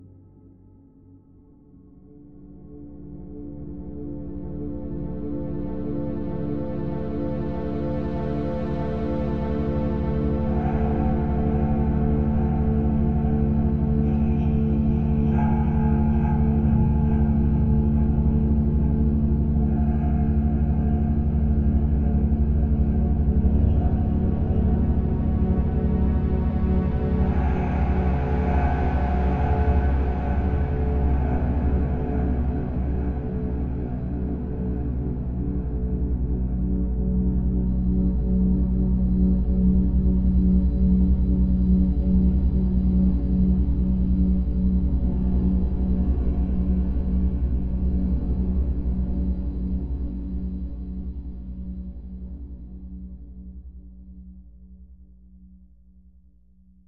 choir c